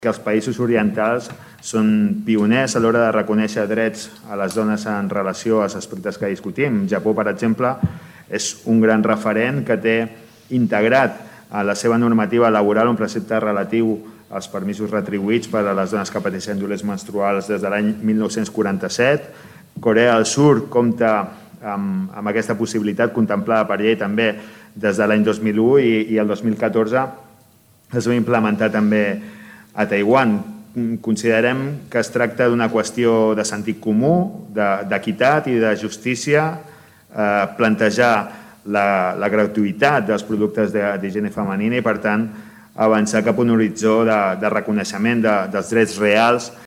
Finalment, el regidor Salvador Giralt exposa el vot positiu.